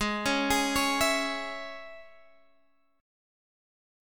Absus4#5 chord